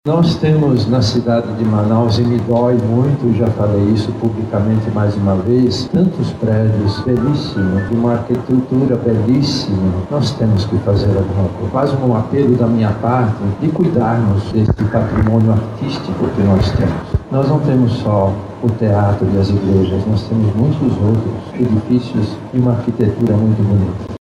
Durante a entrega, o Arcebispo Metropolitano de Manaus e Cardeal da Amazônia, Dom Leonardo Steiner, defendeu a restauração e manutenção do patrimônio histórico da cidade.